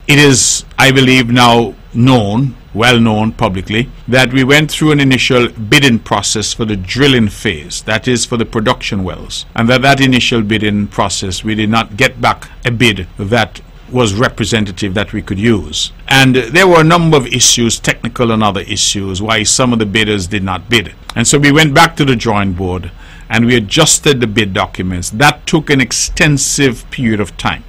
Premier of Nevis, Hon. Mark Brantley.